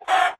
chickenhurt2.ogg